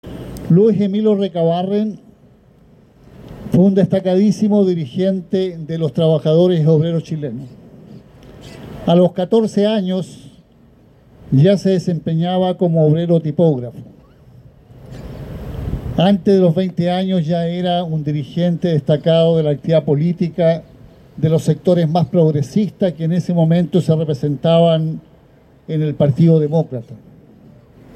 Junto a miembro de la Comisión Política del Partido Comunista y acompañado de una delegación de dirigencias comunales, regionales y militantes, el presidente de la colectividad, Lautaro Carmona, dio cuenta del aporte histórico y político de Luis Emilio Recabarren, fundador del partido de izquierda, a cien años de su fallecimiento.
En el sector del Parque Almagro donde se emplaza su monumento, el timonel destacó la sobresaliente trayectoria de Recabarren, símbolo de vida y pensamiento obrero: